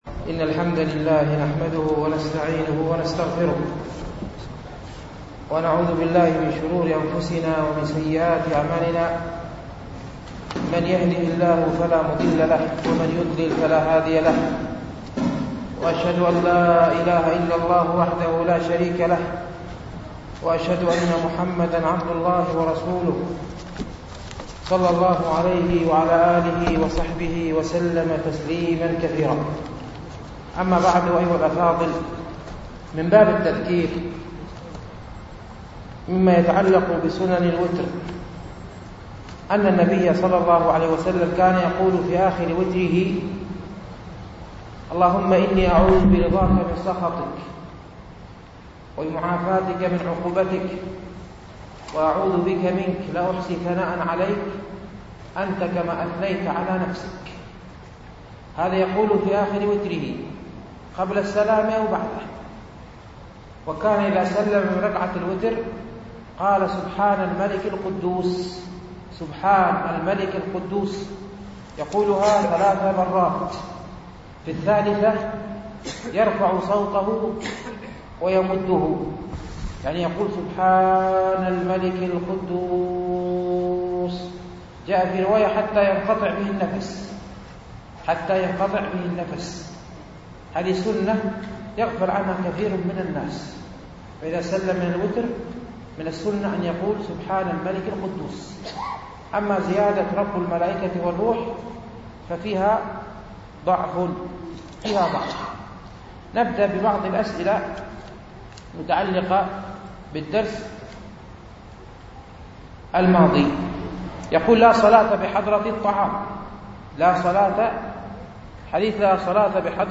شرح رياض الصالحين ـ الدرس الرابع والثمانون